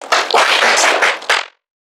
NPC_Creatures_Vocalisations_Infected [33].wav